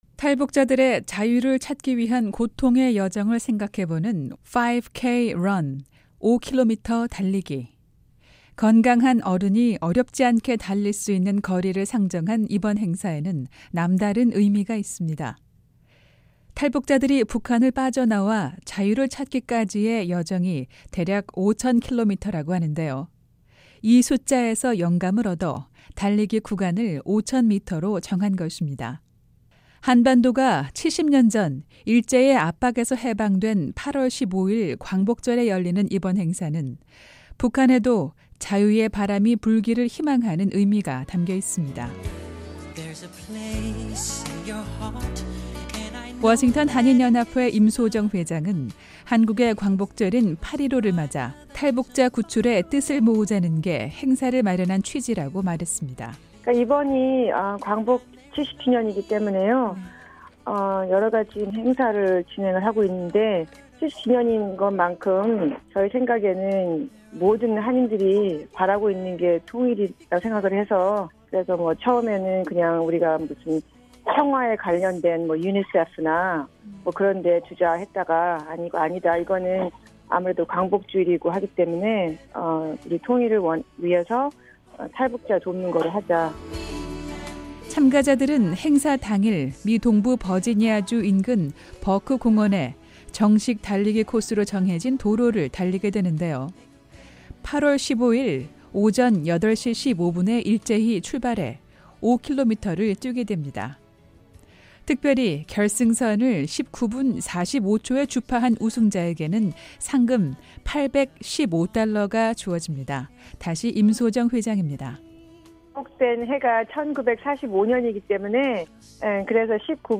매주 화요일 화제성 소식을 전해 드리는 `뉴스 투데이 풍경'입니다. 워싱턴 지역 한인들이 다음달 70주년을 맞는 한반도 광복절을 계기로 탈북자들을 돕기 위한 달리기 행사를 계획하고 있습니다.